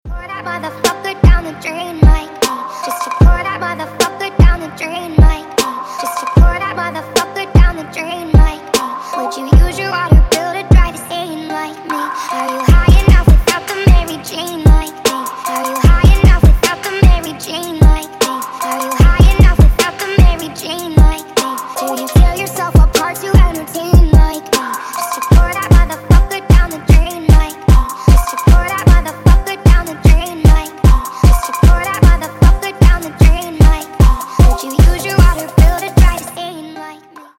• Качество: 224, Stereo
Хип-хоп
Electronic
Trap
Rap